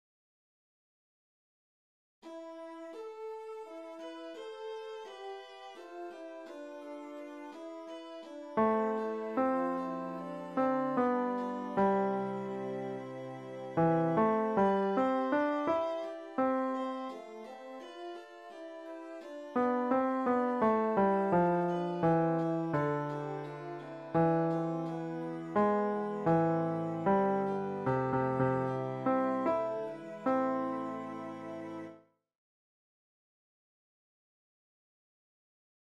Chorproben MIDI-Files 481 midi files